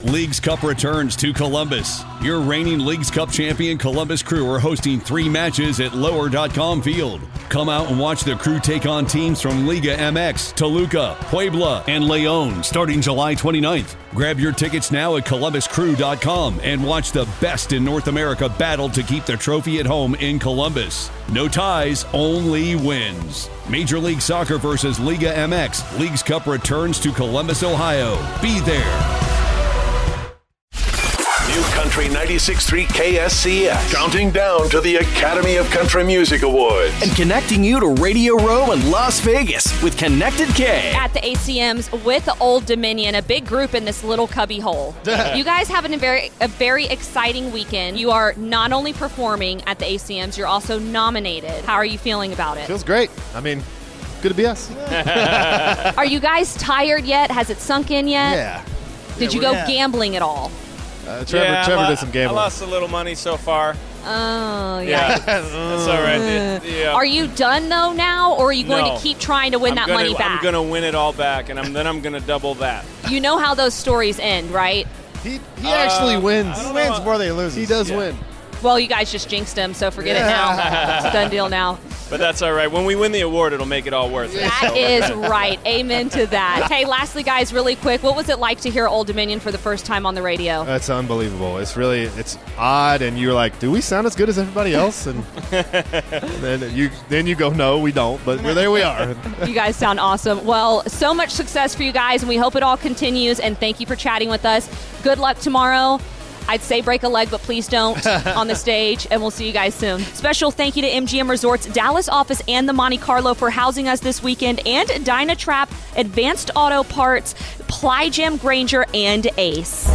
ACM Interview Old Dominion